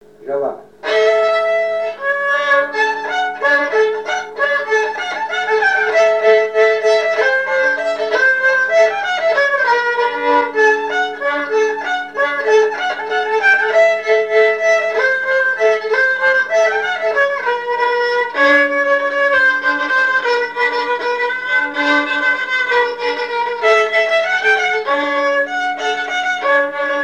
Mémoires et Patrimoines vivants - RaddO est une base de données d'archives iconographiques et sonores.
danse : polka piquée
Pièce musicale inédite